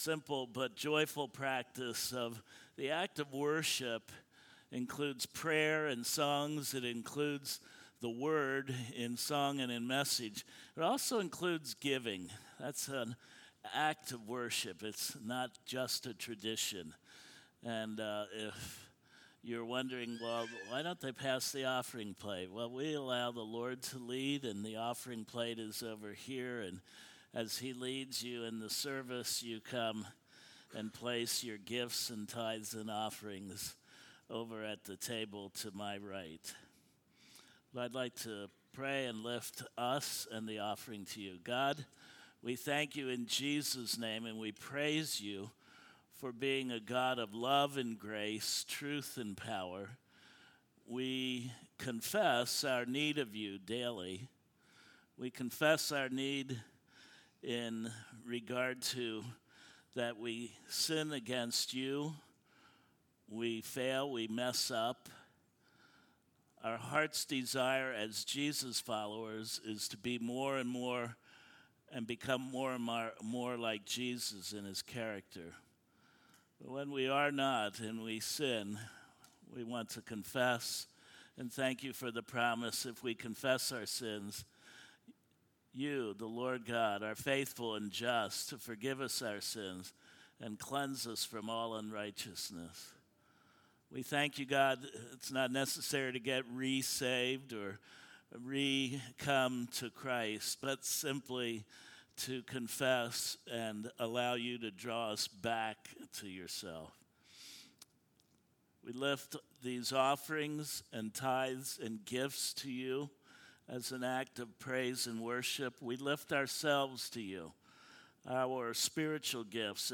Passage: Psalm 145:1-21 Service Type: Sunday Morning Worship